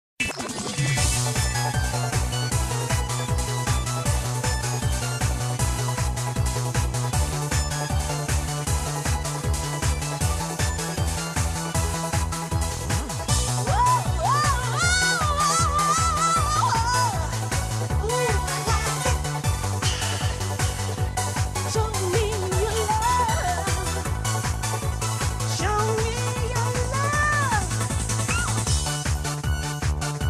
a techno arrangement of the sixth and seventh bars